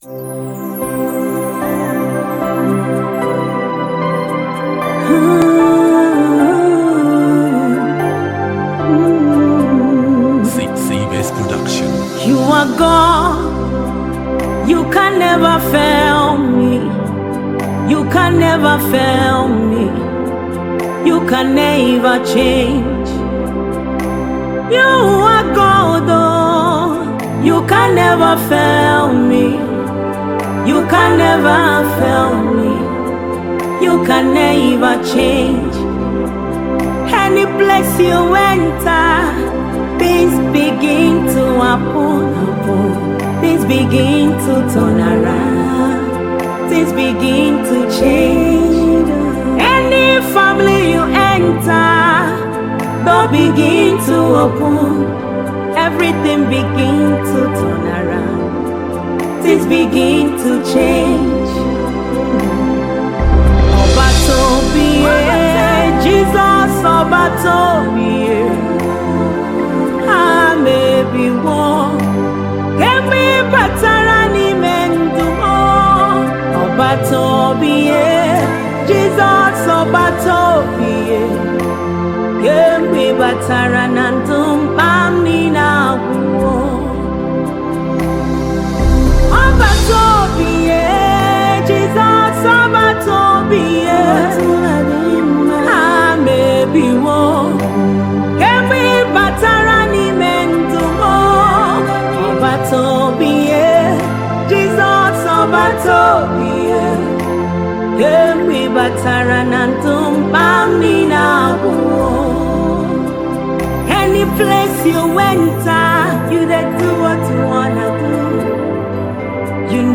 February 19, 2025 Publisher 01 Gospel 0